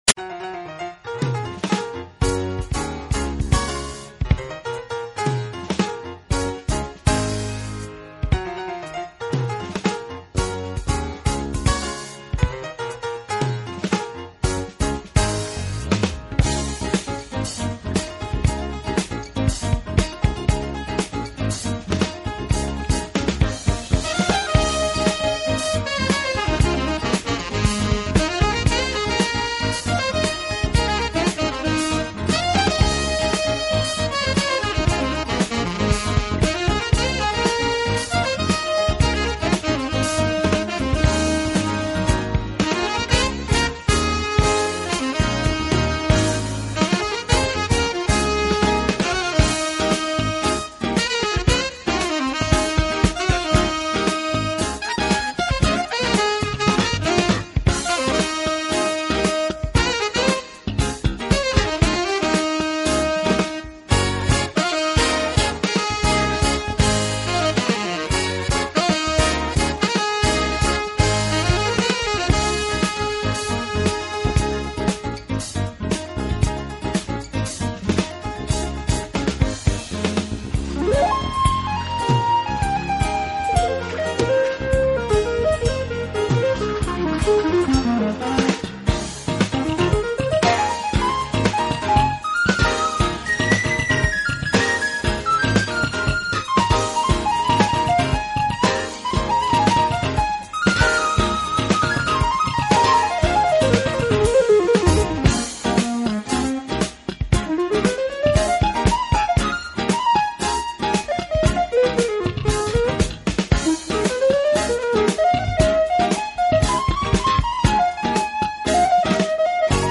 融合了了放克(Funk)，R&B，Fusion，摇滚和电声爵士等音乐元素，键盘手